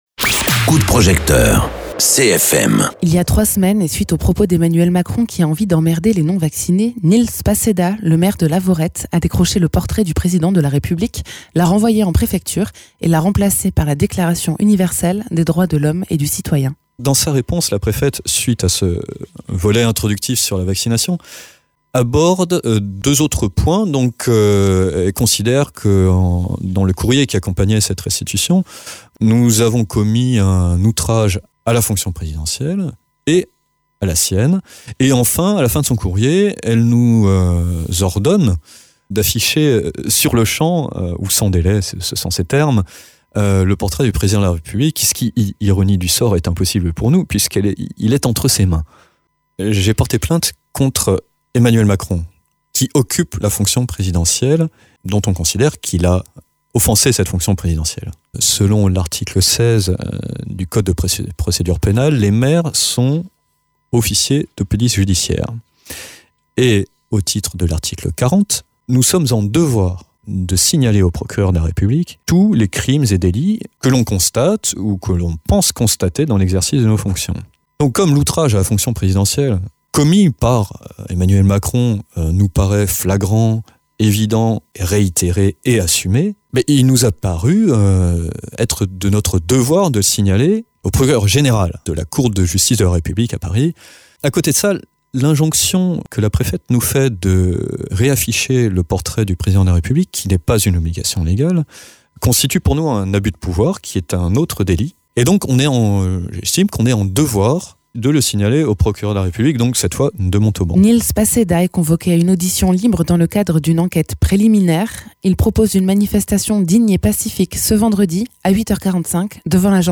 Interviews
Invité(s) : Nils Passedat, maire de Lavaurette